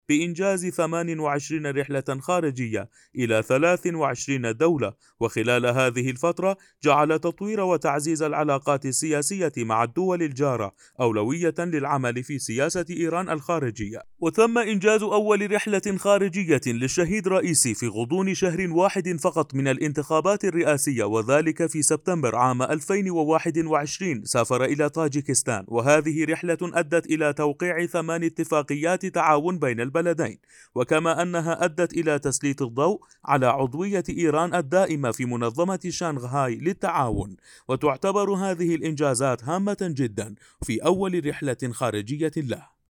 Male
Adult
informative